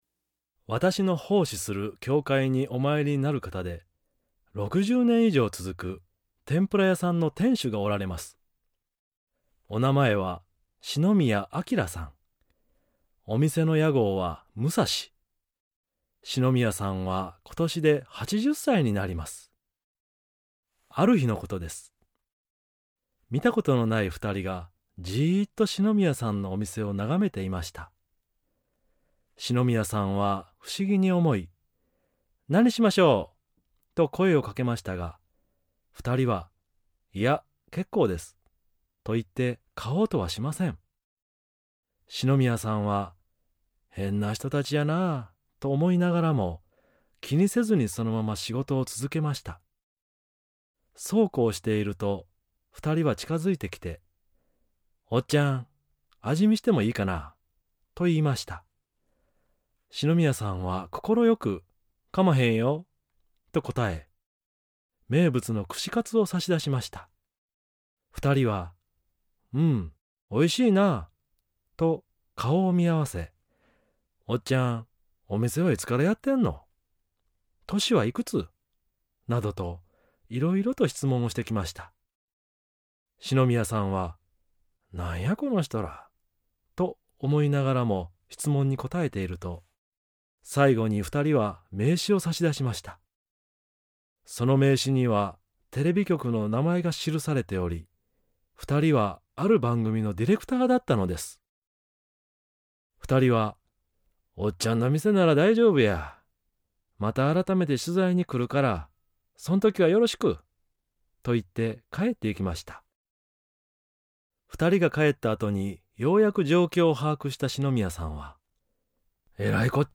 ●先生のおはなし